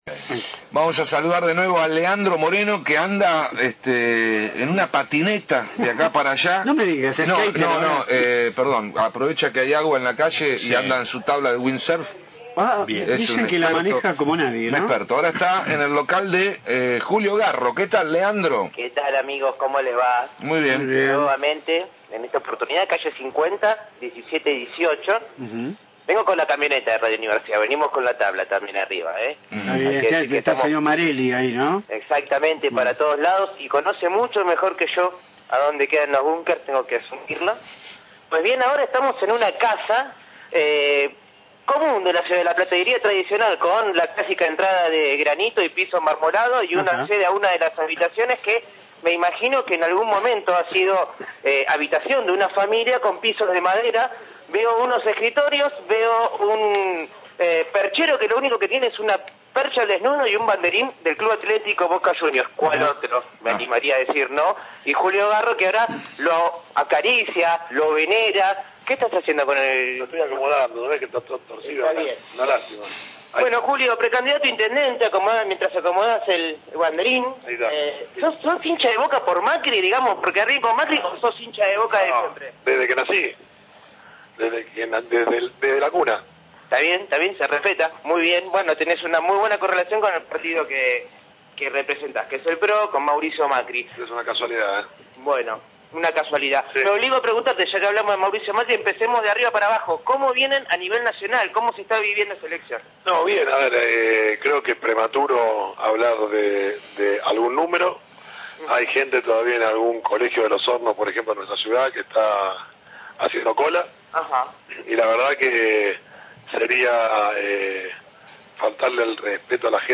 Julio Garro en el móvil de Radio Universidad – Radio Universidad